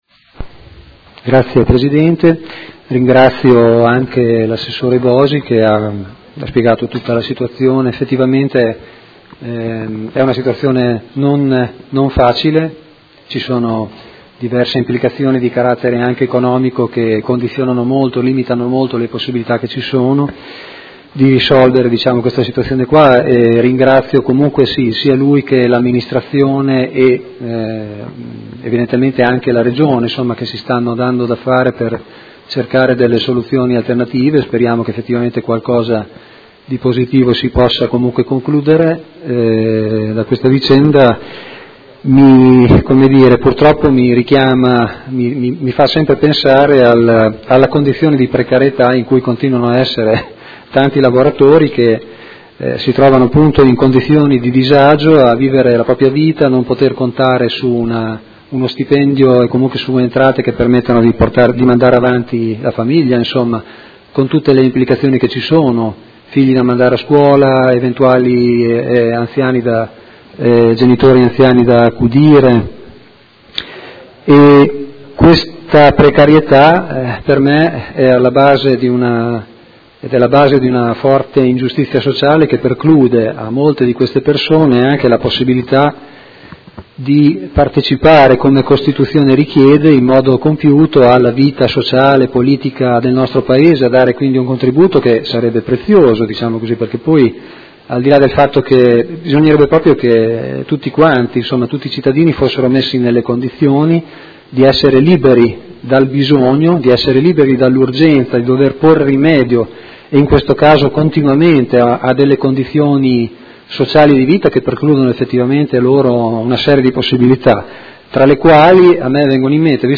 Seduta del 17/11/2016. Conclude interrogazione dei Consiglieri Malferrari e Trande (P.D.) avente per oggetto: Trenkwalder: mancato pagamento stipendi